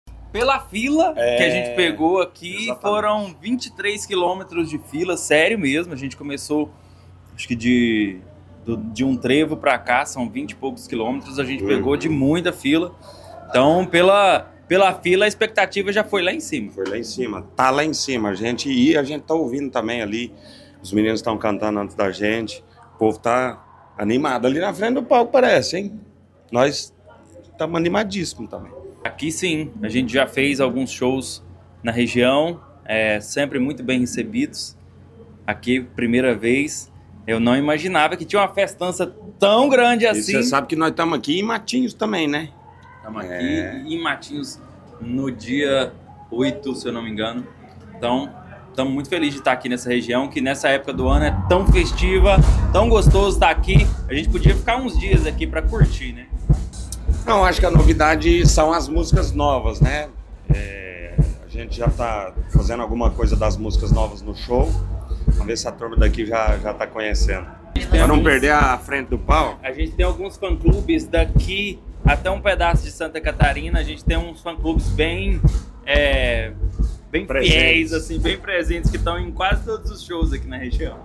Sonora da dupla Israel e Rodolffo sobre o show desta sexta-feira pelo Verão Maior Paraná, em Pontal do Paraná